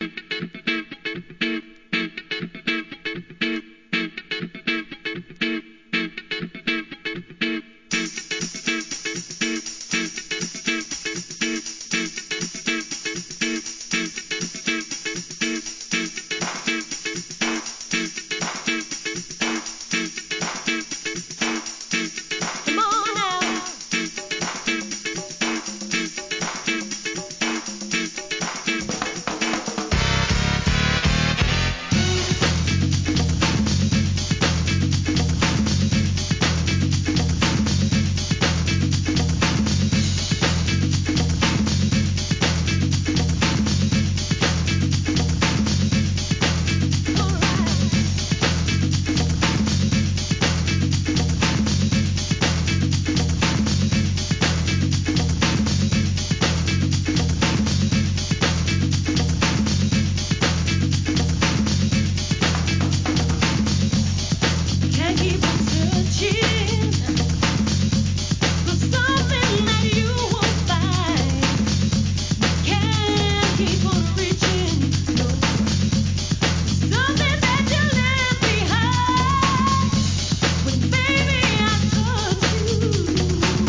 HIP HOP/R&B
1994年、疾走感あるFUNKYなトラックで歌うUK R&B!!